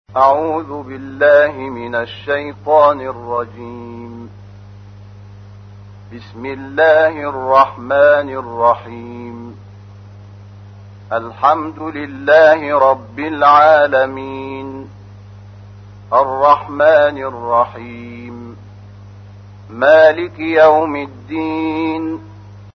تحميل : 1. سورة الفاتحة / القارئ شحات محمد انور / القرآن الكريم / موقع يا حسين